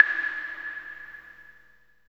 12 CLAVE  -L.wav